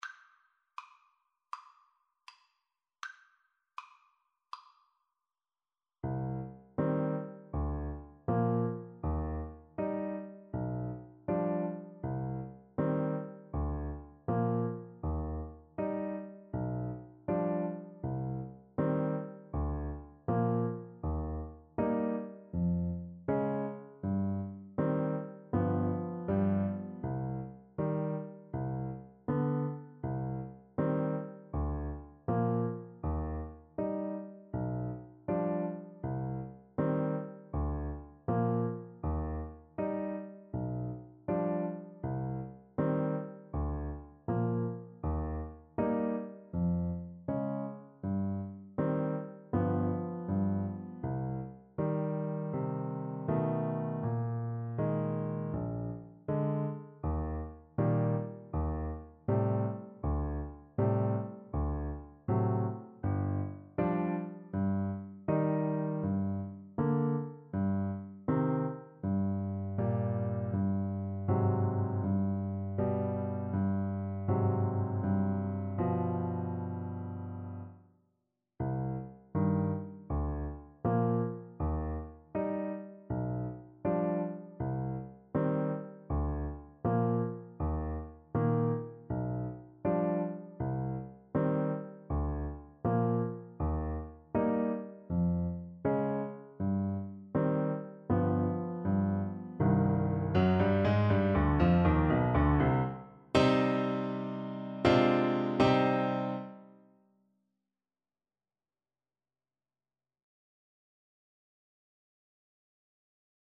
Play (or use space bar on your keyboard) Pause Music Playalong - Piano Accompaniment Playalong Band Accompaniment not yet available reset tempo print settings full screen
C minor (Sounding Pitch) A minor (Alto Saxophone in Eb) (View more C minor Music for Saxophone )
Andante = c.80
Classical (View more Classical Saxophone Music)